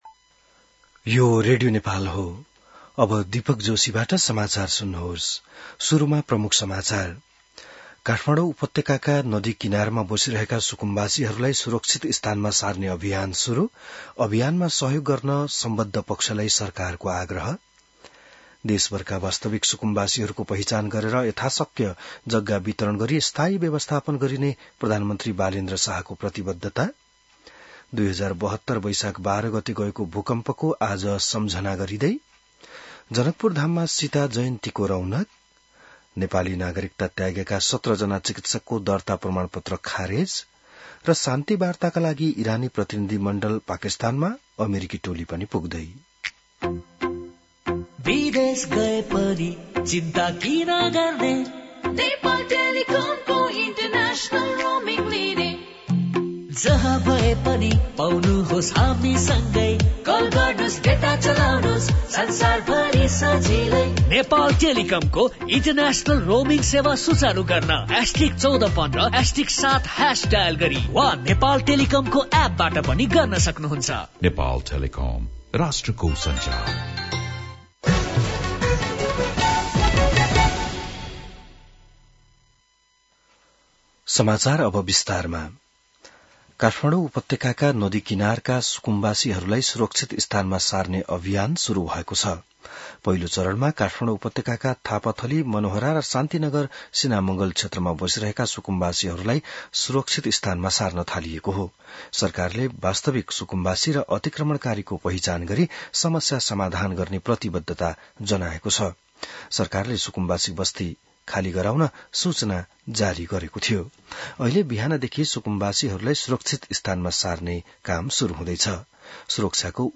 बिहान ७ बजेको नेपाली समाचार : १२ वैशाख , २०८३